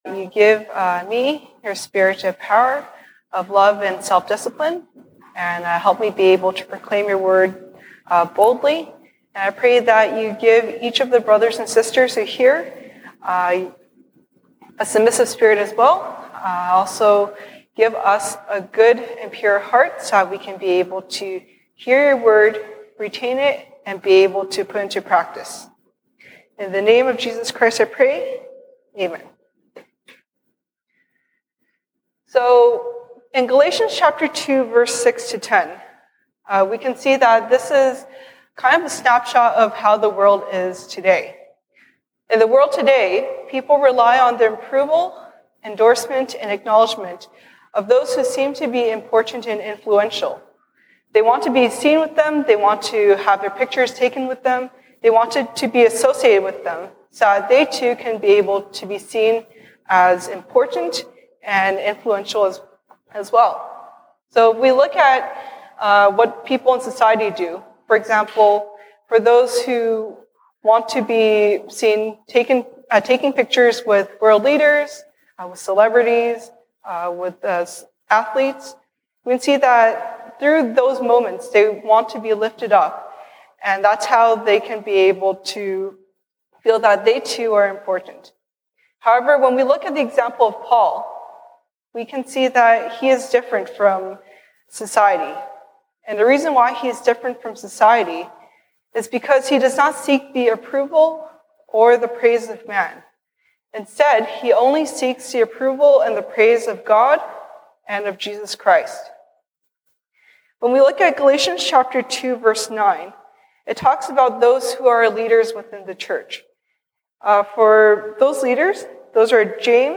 西堂證道 (英語) Sunday Service English: God Confirmed First, Leaders Agreed Later